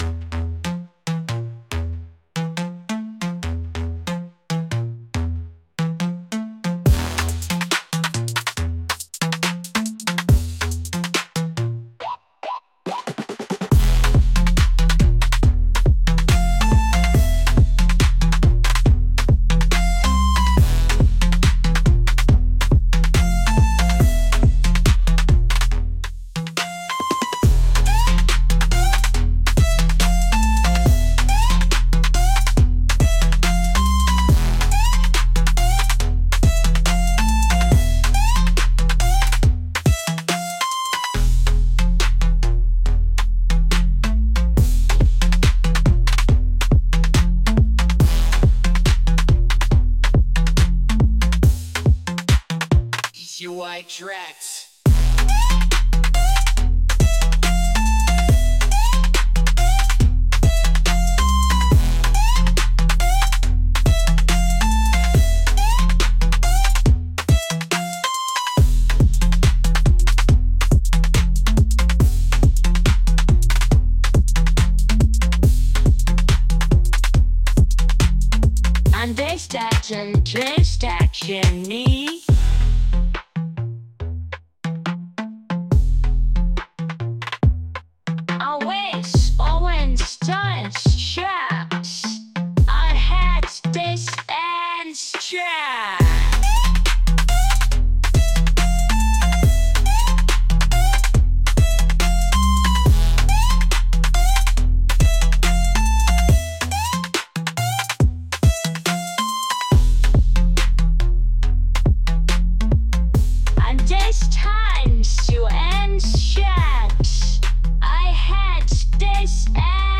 aggressive